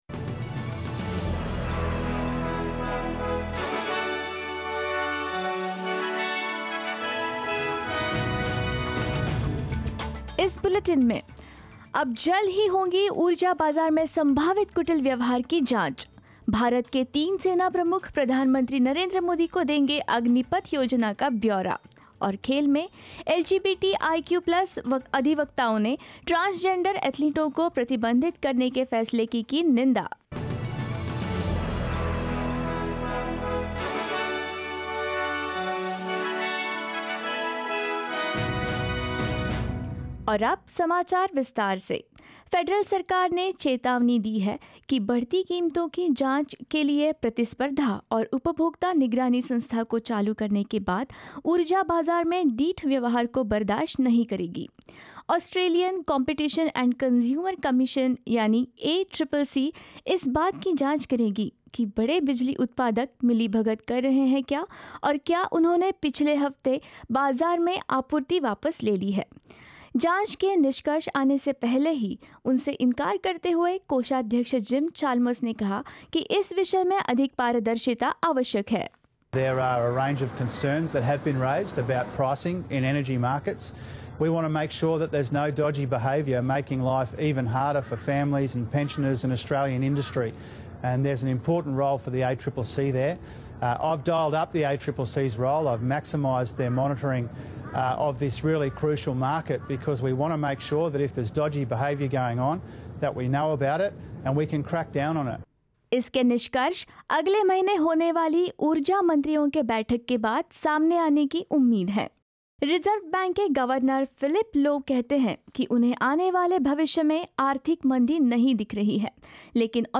In this latest SBS Hindi bulletin: Reserve Bank of Australia Governor Philip Lowe says he doesn't see a recession on the horizon but warns inflation will continue to rise; Indian Prime Minister Narendra Modi to meet Army, Navy and the Air Force chiefs; LGBTIQ+ advocates condemn a decision to ban transgender athletes from women's competitions and more.